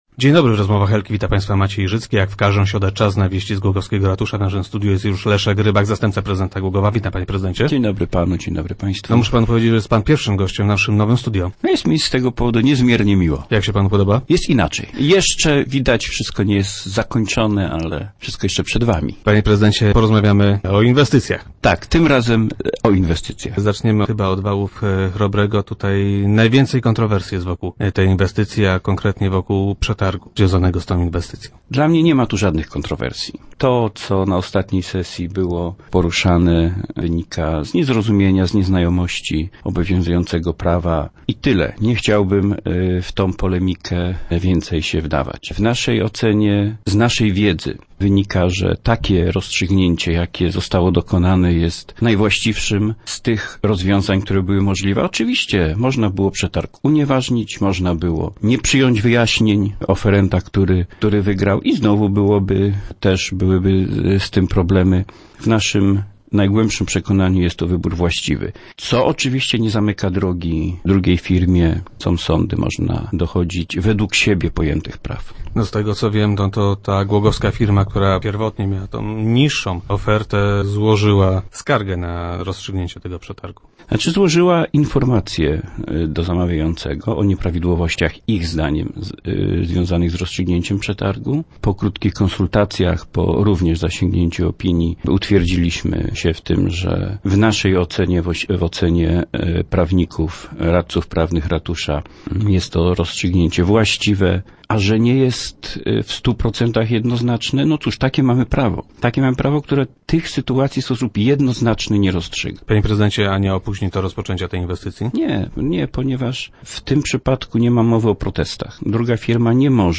Opozycja ma wątpliwości co do legalności przetargu. - Dla mnie nie my tu jednak żadnych kontrowersji - twierdzi wiceprezydent Leszek Rybak, który był gościem Rozmów Elki.
W naszej ocenie rozstrzygnięcie przetargu jest najwłaściwszym z tych rozwiązań, jakie były możliwe - mówił na radiowej antenie wiceprezydent Rybak.